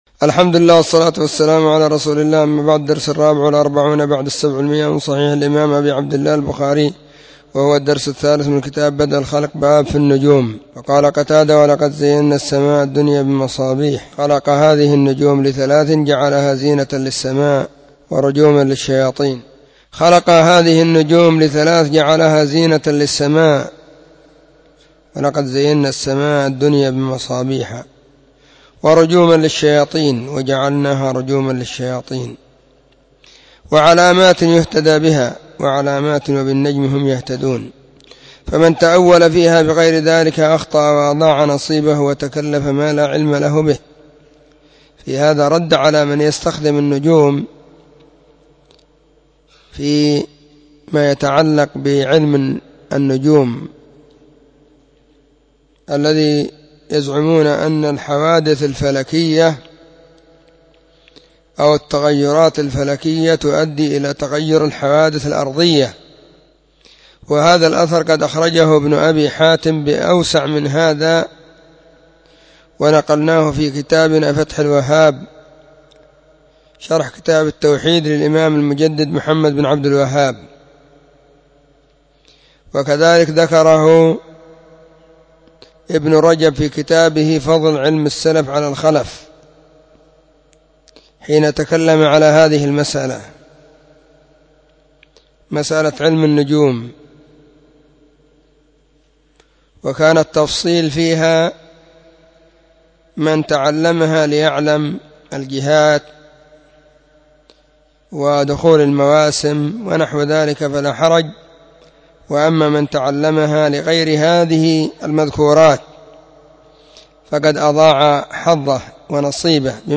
🕐 [بين مغرب وعشاء – الدرس الثاني]
🕐 [بين مغرب وعشاء – الدرس الثاني] 📢 مسجد الصحابة – بالغيضة – المهرة، اليمن حرسها الله.